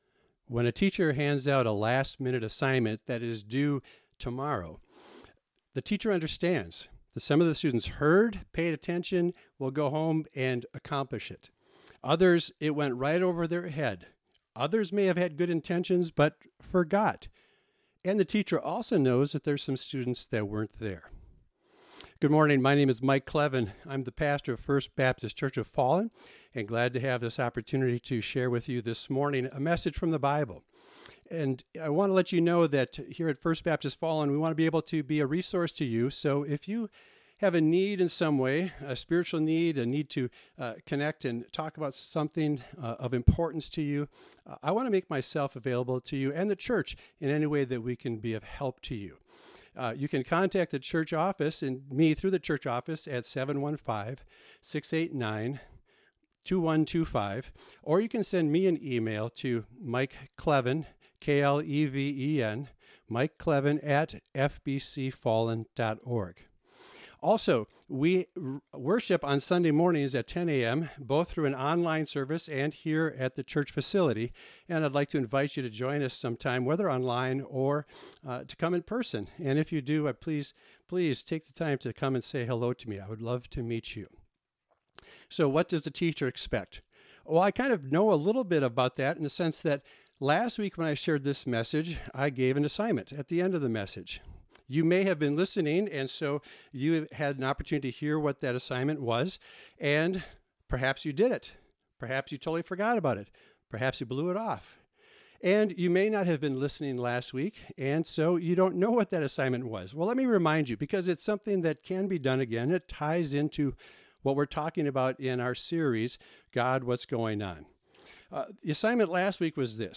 #6 • Matthew 22:34-40 Downloads & Resources • Video File • Audio File The MP3 audio file is the radio version of the message recorded for broadcast on WILLIE 105.7 AM, Siren, Wisconsin.